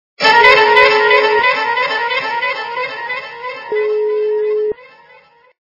» Звуки » звуки для СМС » Звук - Неожиданное загадочное оповещение
При прослушивании Звук - Неожиданное загадочное оповещение качество понижено и присутствуют гудки.